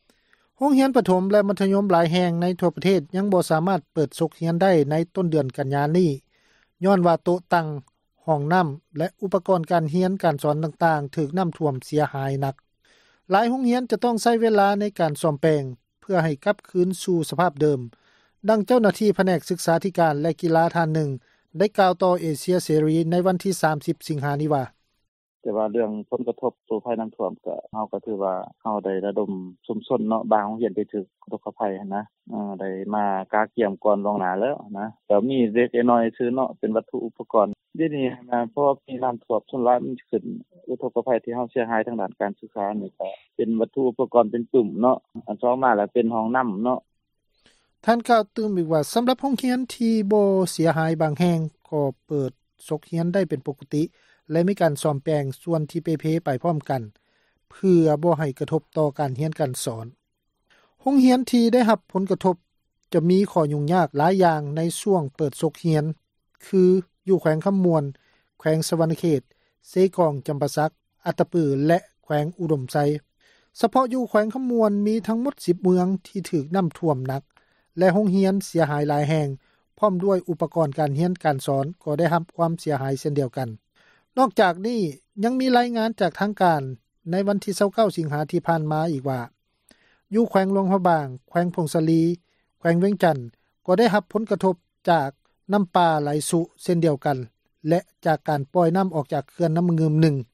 ໂຮງຮຽນປະຖົມ ແລະ ມັທຍົມຫລາຍແຫ່ງ ໃນທົ່ວປະເທດ ຍັງບໍ່ສາມາດເປີດສົກຮຽນໄດ້ ໃນຕົ້ນເດືອນກັນຍາ ນີ້ຍ້ອນວ່າ ໂຕະຕັ່ງ, ຫ້ອງນ້ຳ ແລະອຸປກອນການຮຽນ ການສອນຕ່າງໆ ຖືກນ້ຳຖ້ວມເສັຍຫາຍໜົດ. ຫລາຍໂຮງຮຽນ ຈະຕ້ອງໃຊ້ເວລາສ້ອມແປງ ໃຫ້ກັບຄືນສູ່ສະພາບ ເດີມ, ດັ່ງເຈົ້າໜ້າທີ່ຜແນກສຶກສາທິການ ແລະກິລາ ທ່ານນຶ່ງ ໄດ້ກ່າວຕໍ່ເອເຊັຍເສຣີ ໃນວັນທີ 30 ສິງຫາ ນີ້ວ່າ: